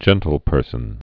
(jĕntl-pûrsən)